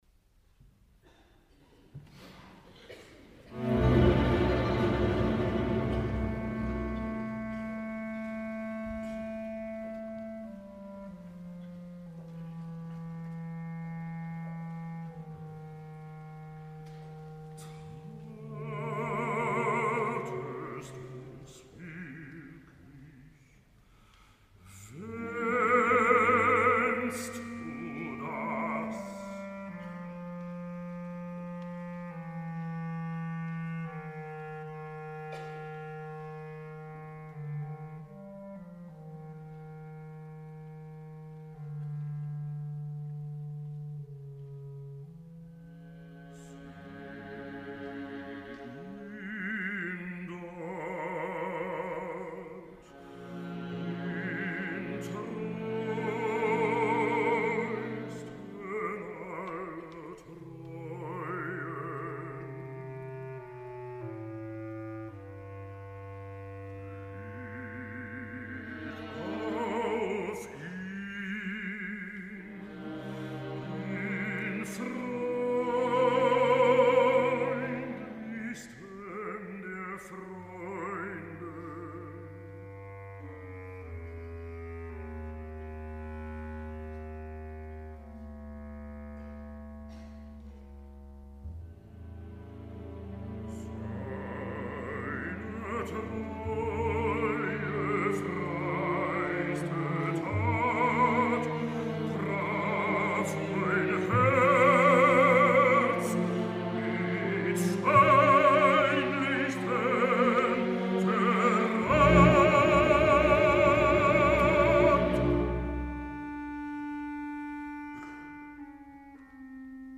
El baríton alemany Matthias Goerne ha participat aquest cap de setmana passat en els concerts de la temporada de la ONE (Orquestra Nacional de España) cantant dos fragment de Richard Wagner.
El Rei Marke no ha de lluitar mai contra l’orquestra, el seu lament impressionant té un coixí orquestral soterrat, sàviament acompanyat per intervencions solistes del clarinet baix, oboè i corn anglès, per tant Goerne pot matisar en un fraseig minuciós només a l’abast d’un immens liederista com ell.
01-marke.mp3